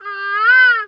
bird_peacock_squawk_soft_01.wav